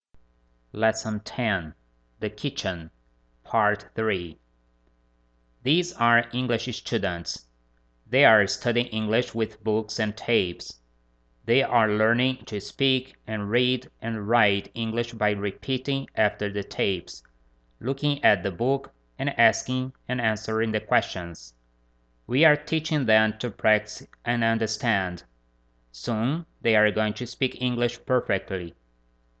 Lesson 10 - The Kitchen